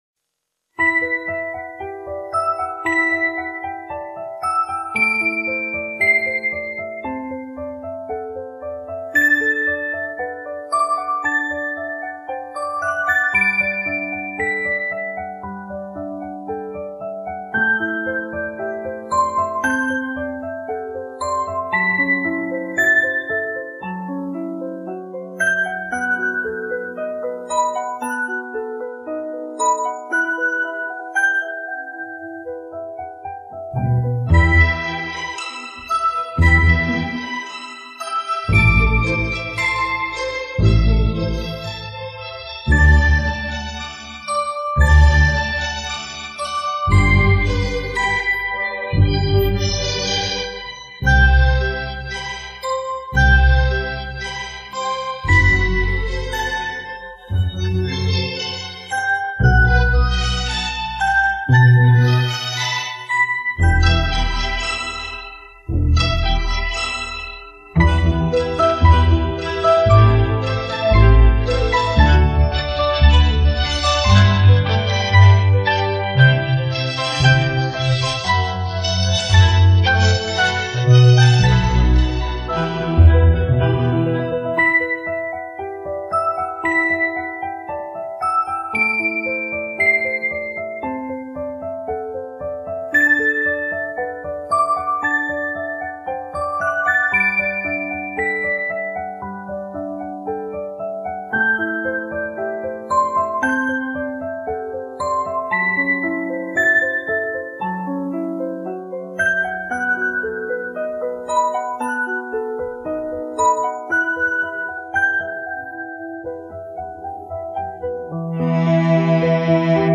triste